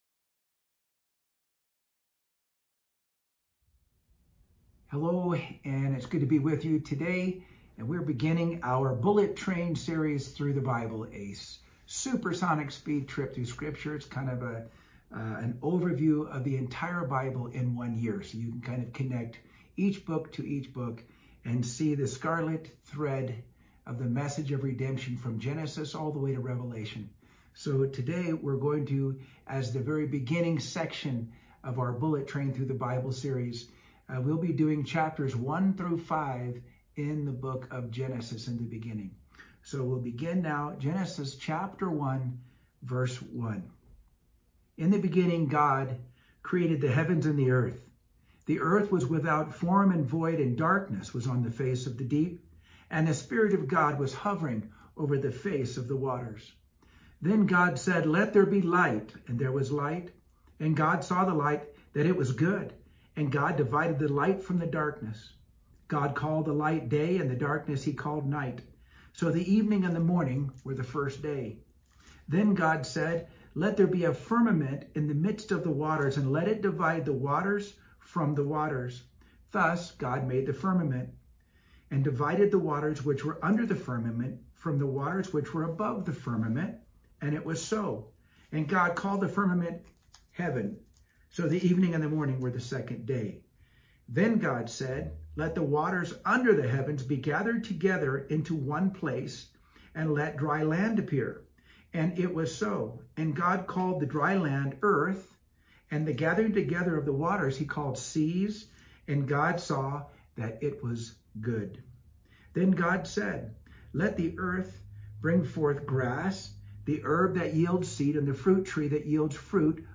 Bullet Train Tour Through the Bible – Genesis – Bible Reading #1